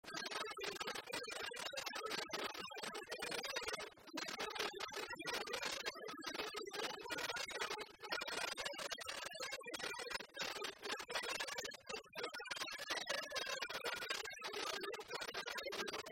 Avant-deux
Résumé instrumental
danse : branle : avant-deux
Pièce musicale inédite